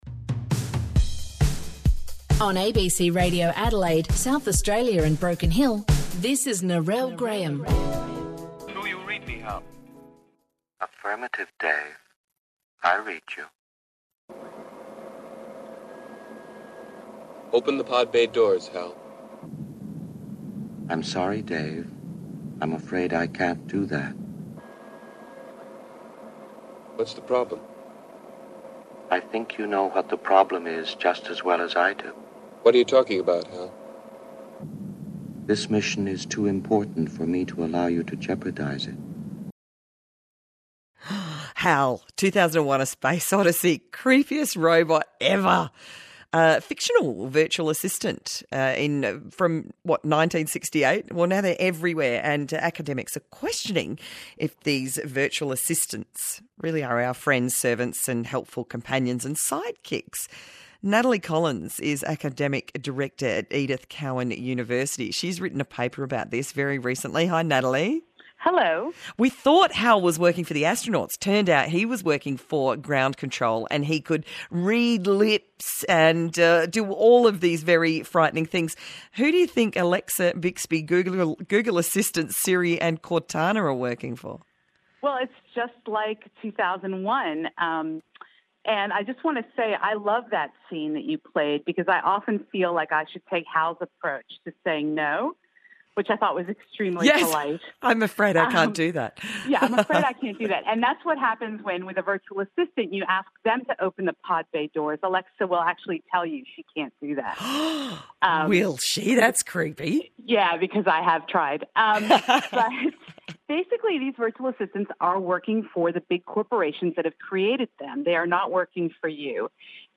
It was my pleasure to be interviewed on ABC Drive Adelaide for a short piece on the marketing of Virtual Assistants. This humorous and pithy interview get some great points across about the overall surveillance in modern life; not just by corporations but by our friends (and maybe parents) too.